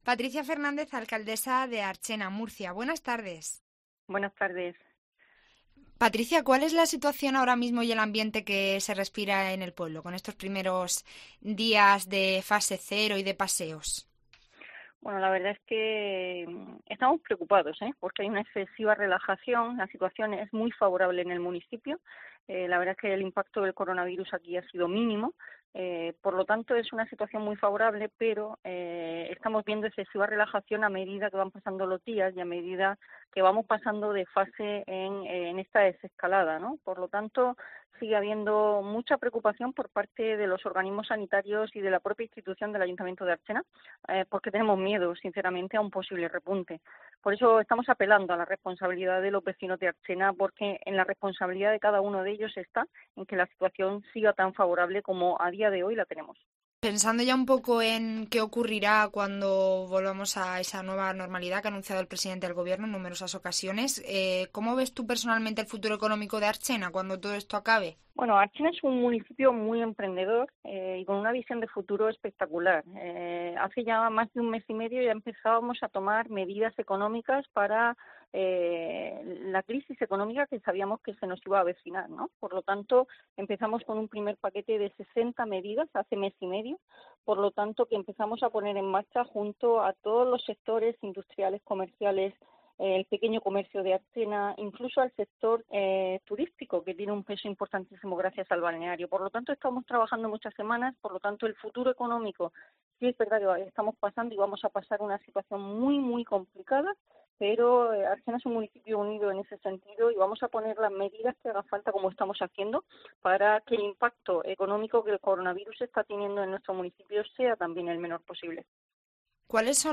Patricia Fernández, alcaldesa de Archena (Murcia), en COPE: “Cerraremos todas las calles que hagan falta"